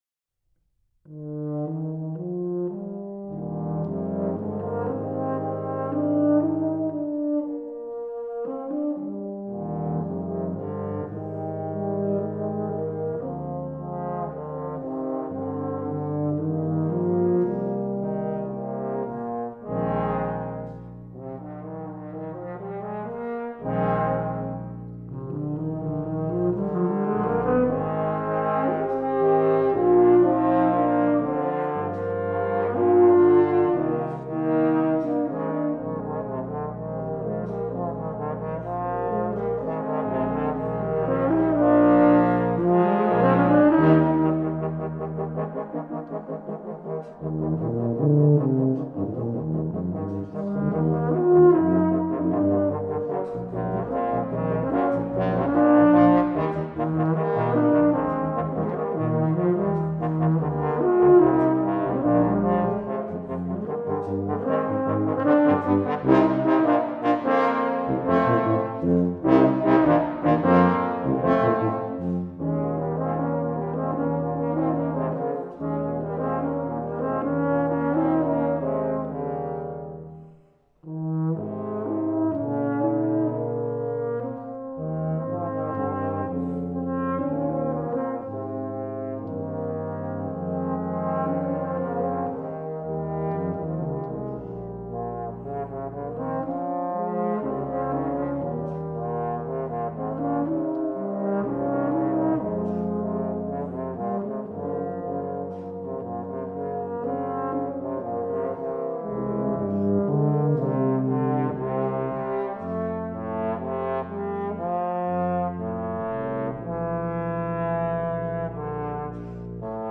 Partitions pour quatuor à vent flexible, registre grave.